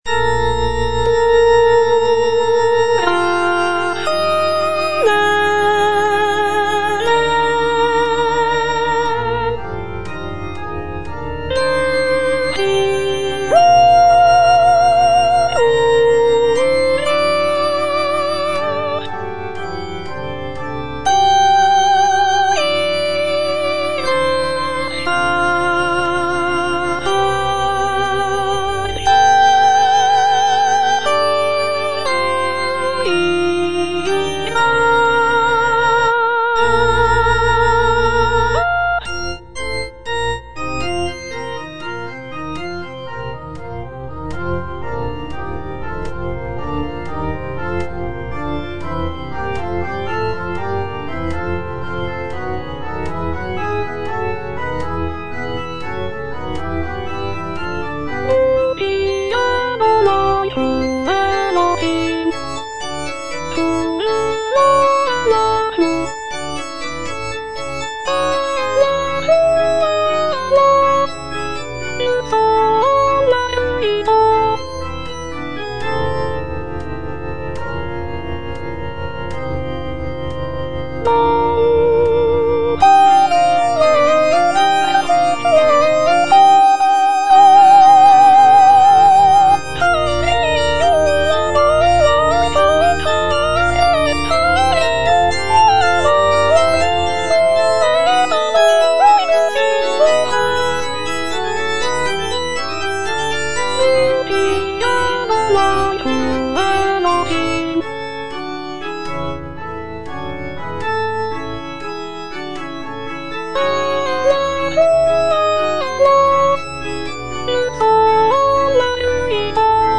(soprano I) (Voice with metronome) Ads stop